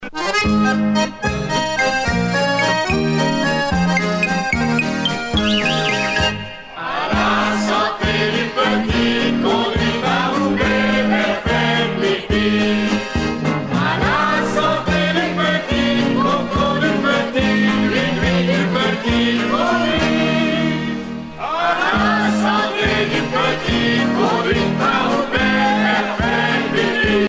danse : valse
Pièce musicale éditée